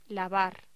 Título Locución: Lavar